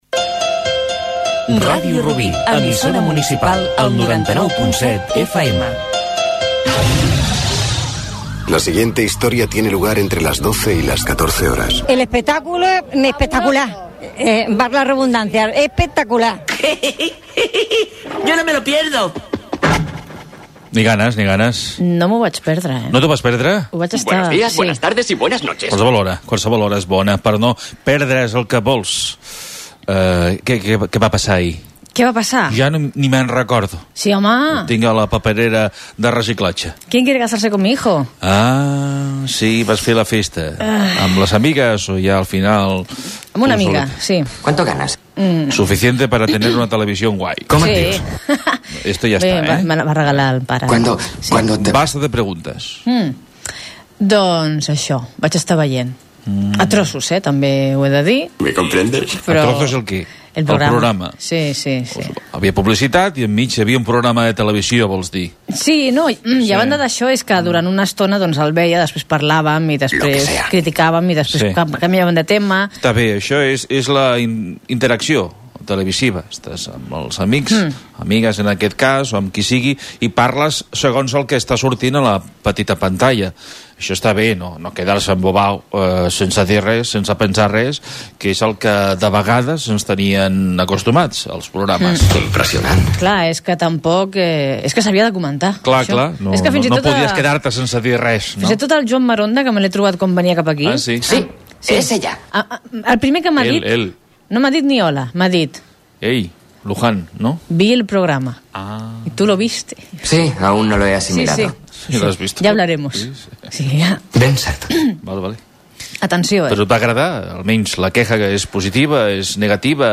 Indicatiu de l'emissora, diàleg sobre un programa televisiu, les classes d'anglès de "Gomaespuminglish" (TVE), indicatiu del programa
Entreteniment